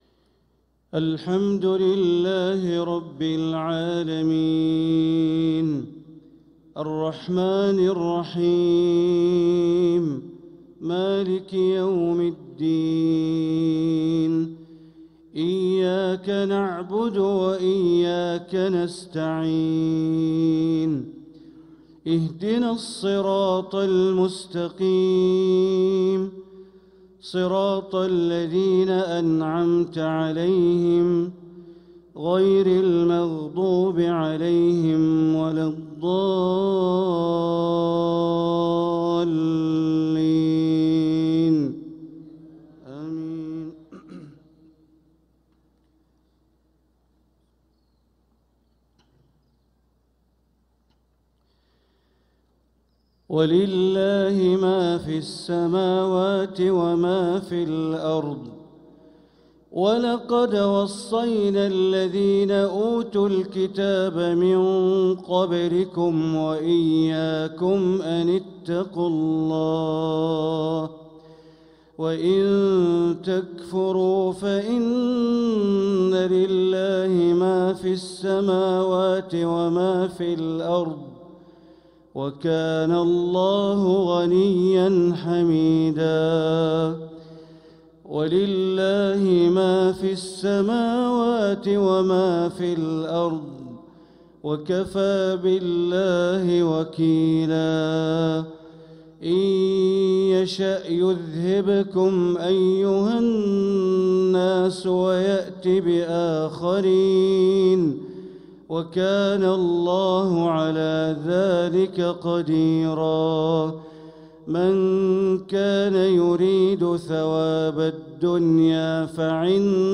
صلاة المغرب للقارئ بندر بليلة 1 صفر 1446 هـ